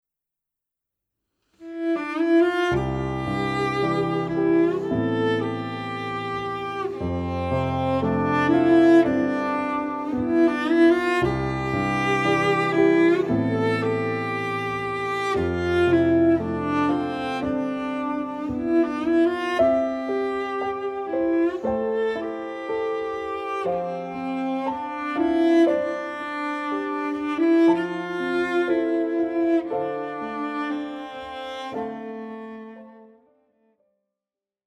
• Besetzung Violoncello und Klavier